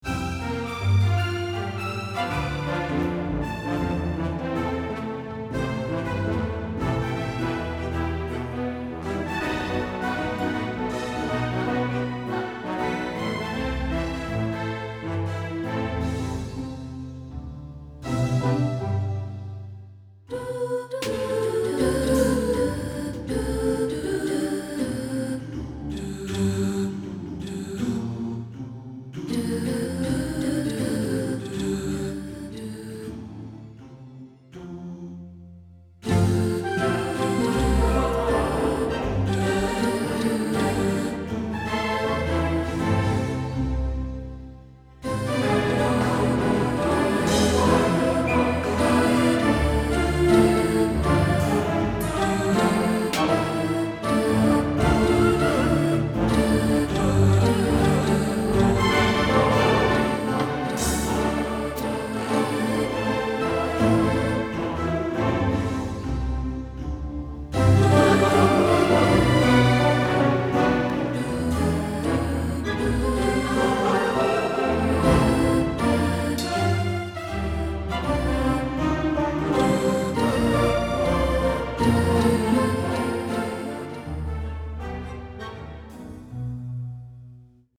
Orchestra & Combo
Girls Choir, Male Choir, Plucked Bass, Percussion